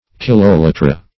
Kiloliter \Kil"o*li`ter\, Kilolitre \Kil"o*li`tre\, n. [F.